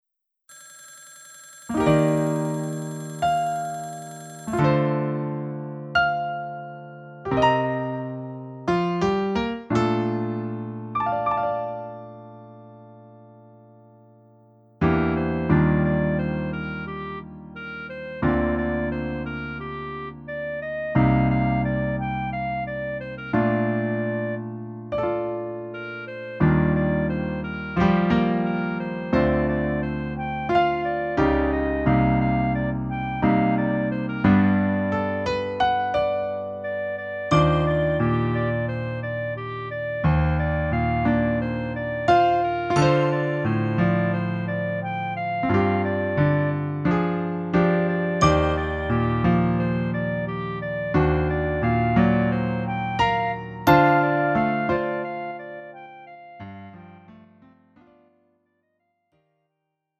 음정 원키 3:05
장르 가요 구분 Lite MR